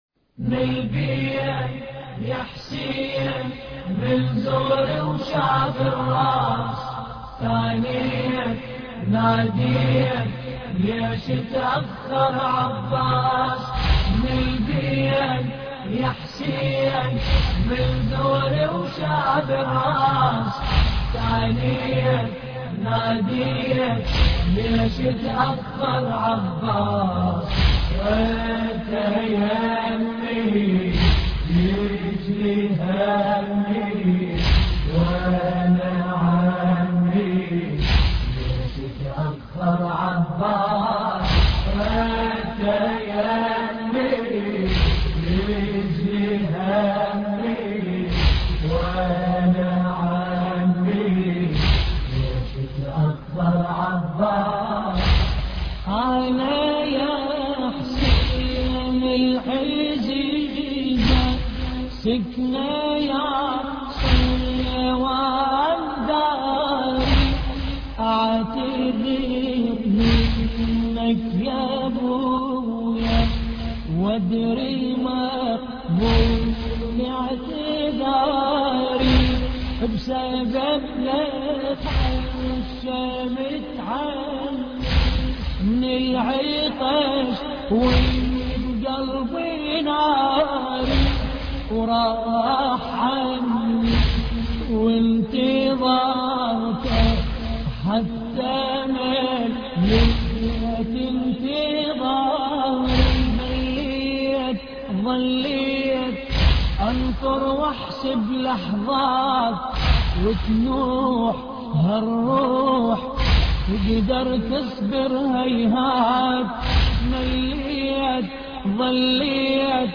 مراثي